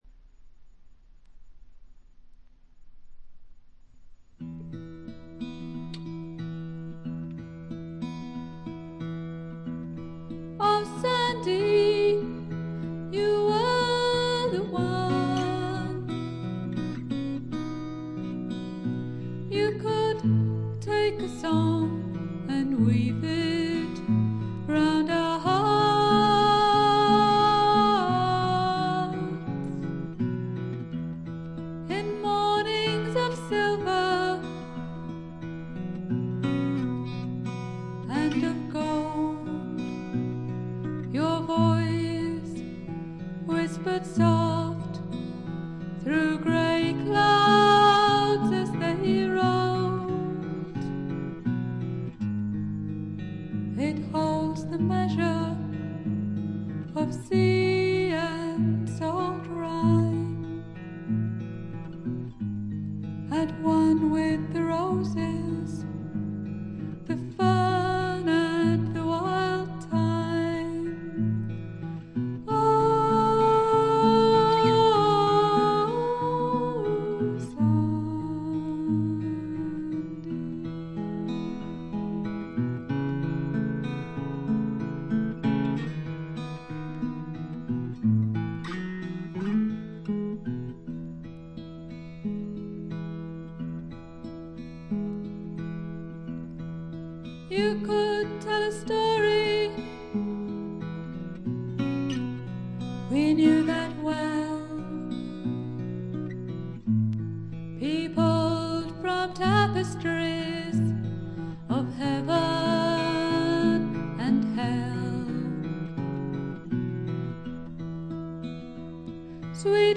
ホーム > レコード：英国 SSW / フォークロック
ところどころで軽いチリプチ程度。
少しざらついた美声がとても心地よいです。
試聴曲は現品からの取り込み音源です。